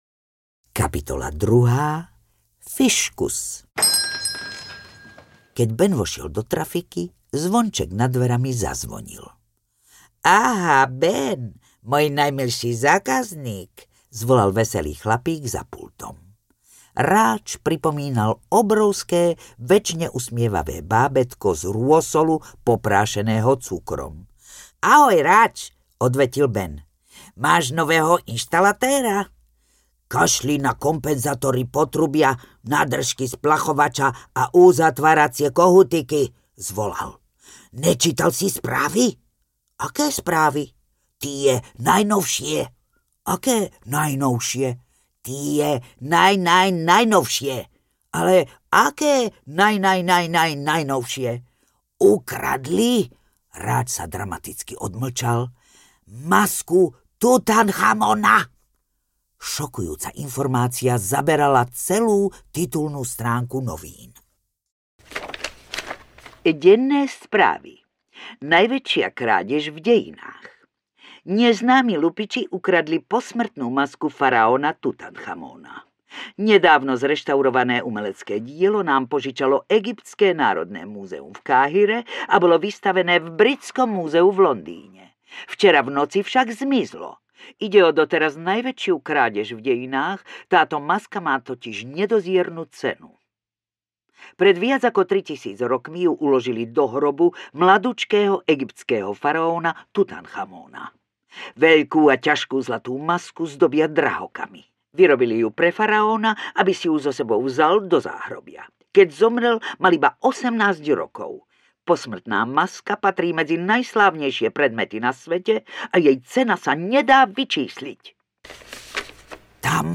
Babka gaunerka opäť v akcii audiokniha
Ukázka z knihy
• InterpretZuzana Kronerová